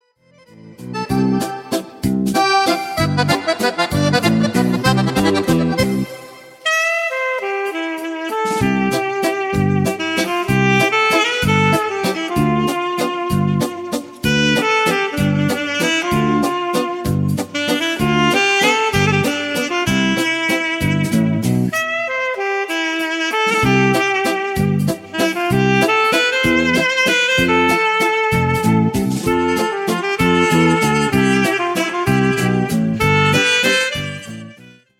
VALZER  (4.00)